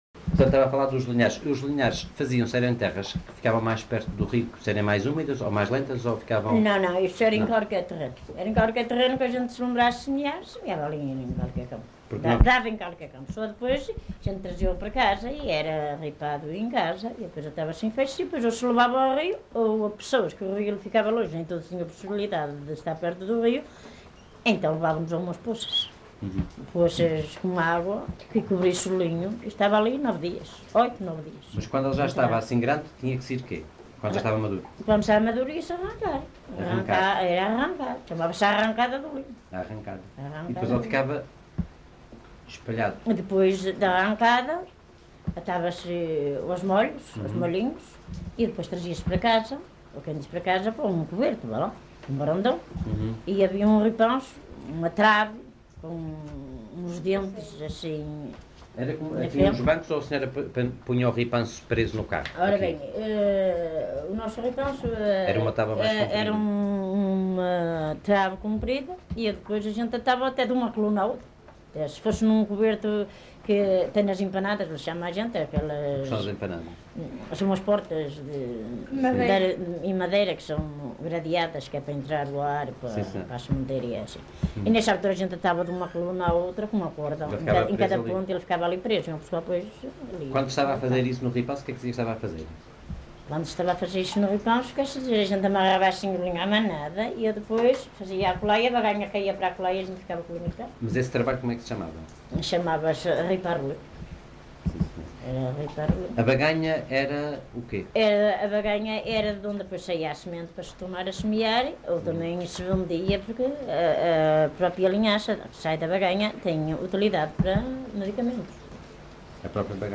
LocalidadeFiscal (Amares, Braga)